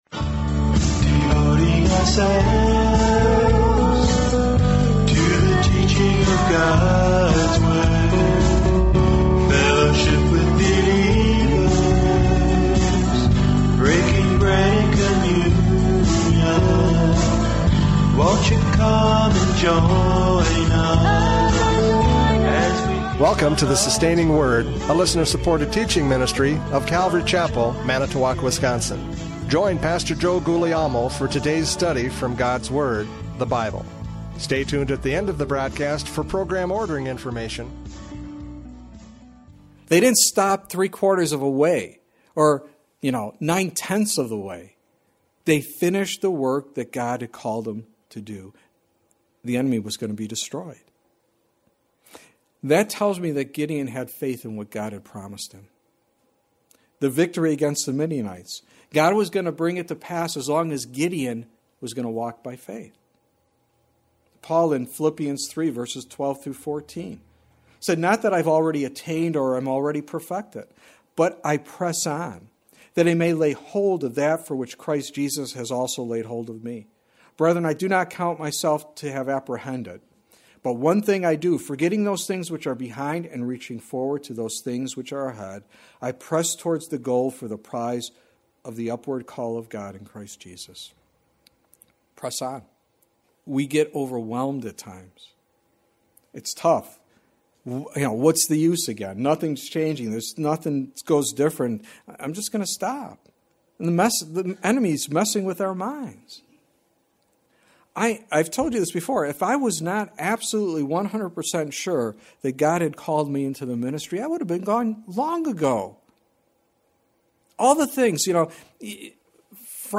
Judges 8:4-21 Service Type: Radio Programs « Judges 8:4-21 Perusing the Enemy!